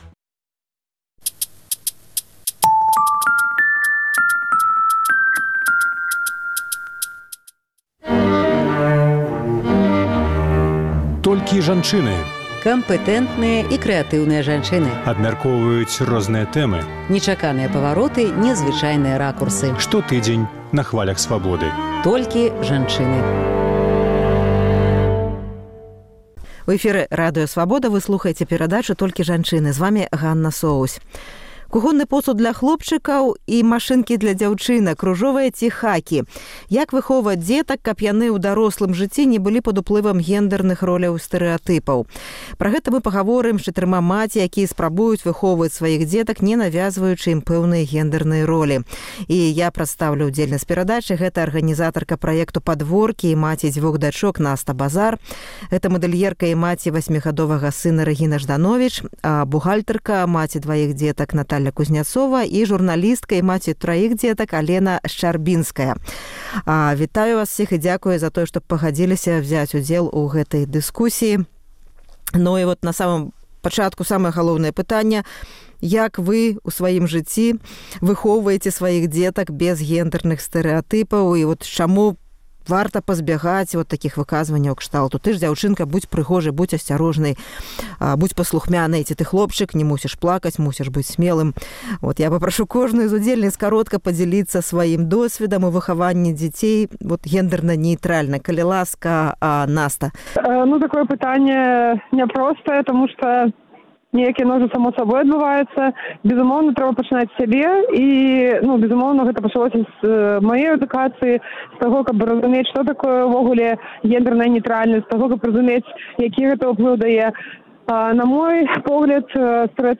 Дыскусія пра гендэрна-нэўтральнае выхаваньне